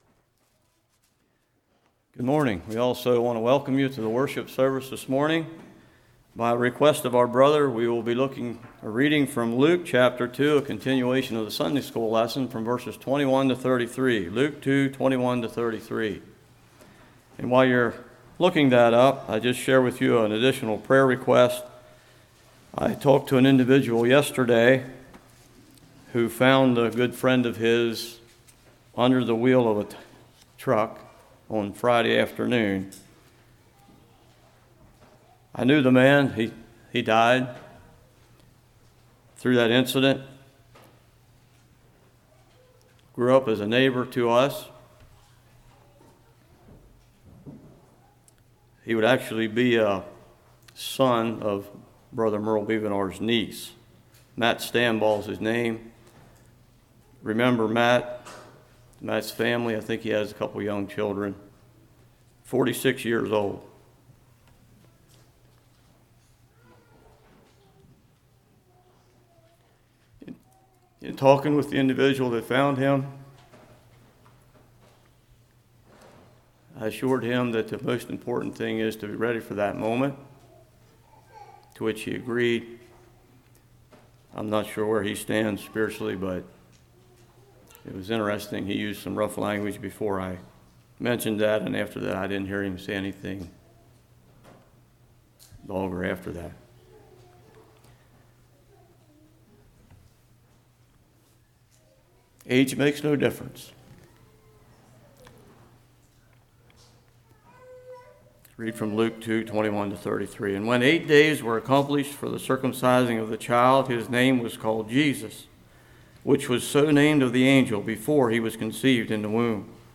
Luke 2:21-33 Service Type: Morning Do we see Jesus as Savior?